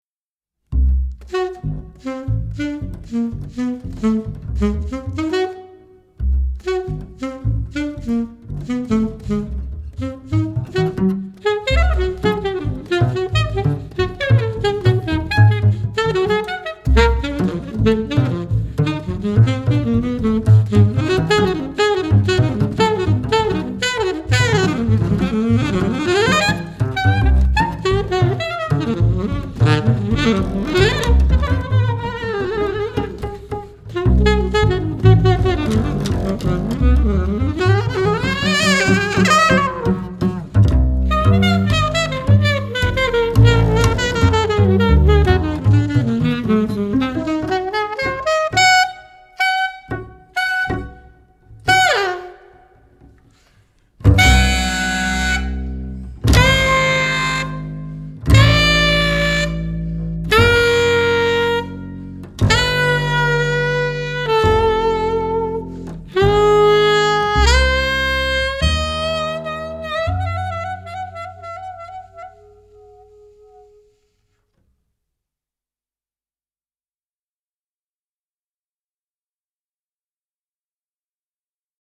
爵士音樂、發燒音樂